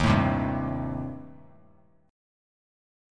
Eb6b5 chord